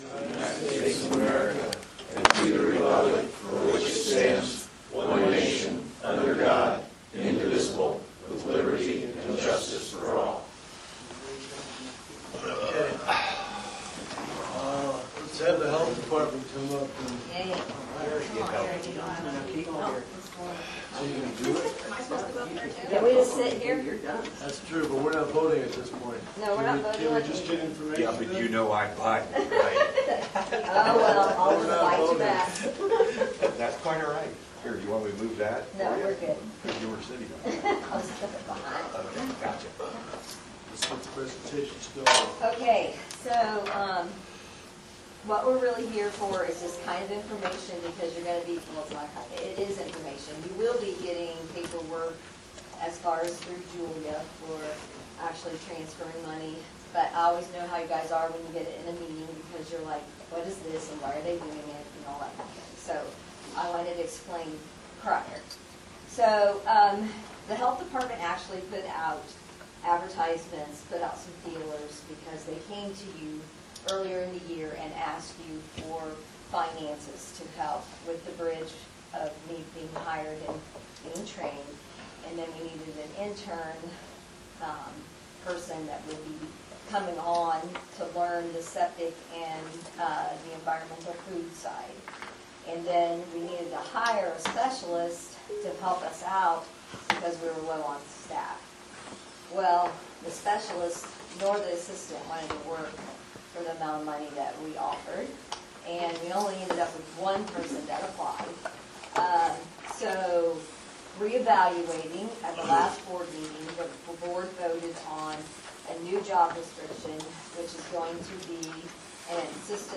County Council Budget Meeting Notes, Oct 3, 2024, 9 am to 12:30+, Veterans, RDC, New Revenue, Food Fight